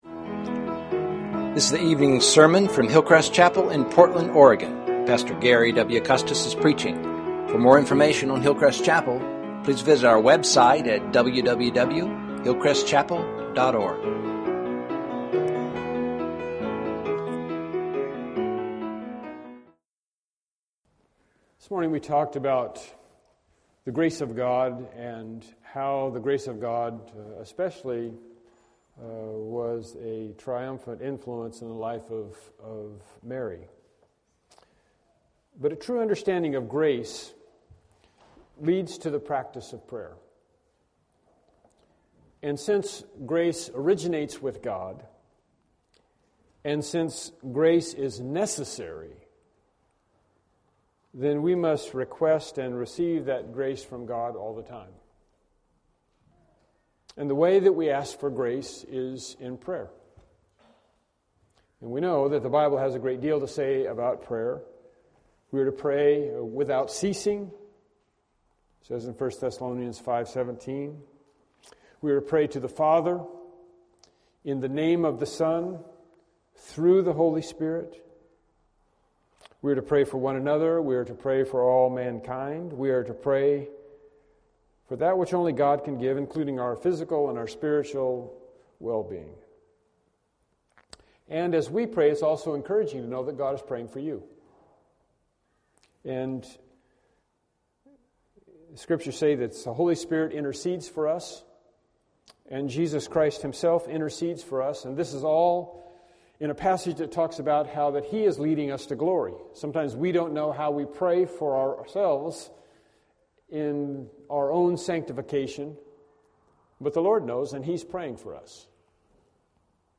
Series: Evening Sermons
Matthew 6:9-13 Service Type: Evening Worship Service « “The Anointing of Jesus” “The Lazarus Problem” »